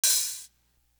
Worst To Worst Open Hat.wav